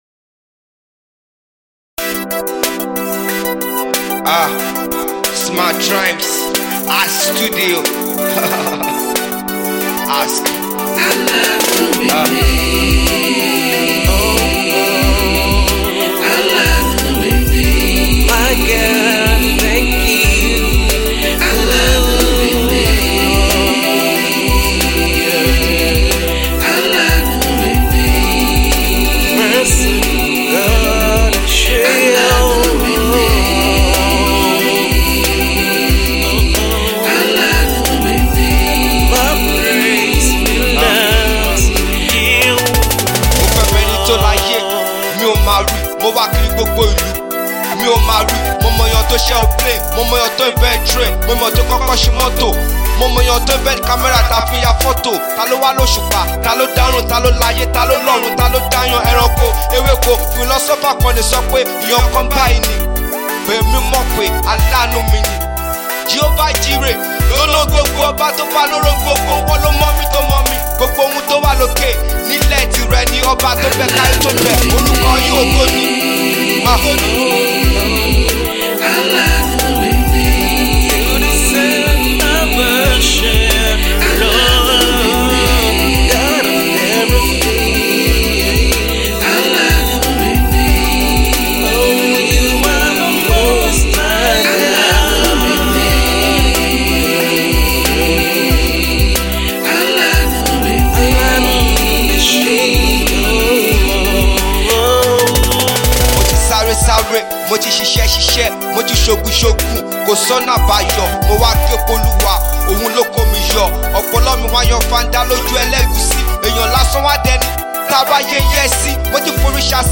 a very young and vibrant rapper